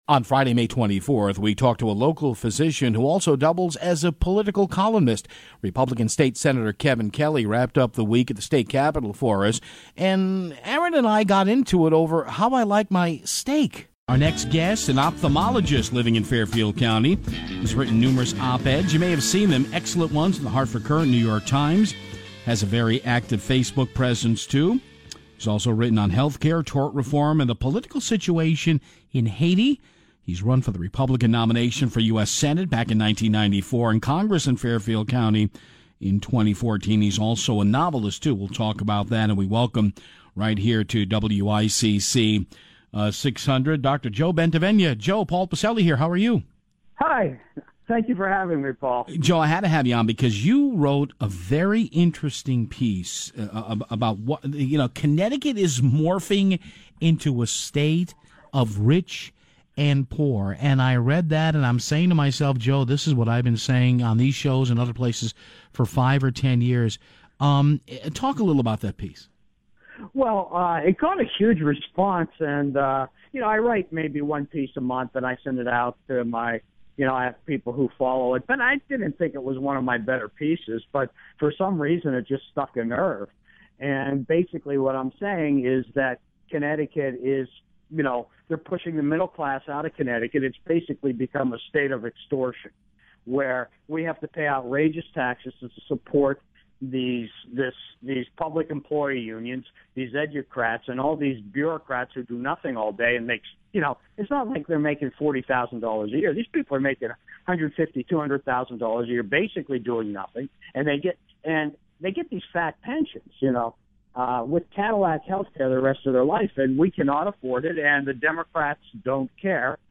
Then, State Senator Kevin Kelly (R-21) joins the show to discuss a new Health Care proposal put before the senate and other happenings at the state Capitol.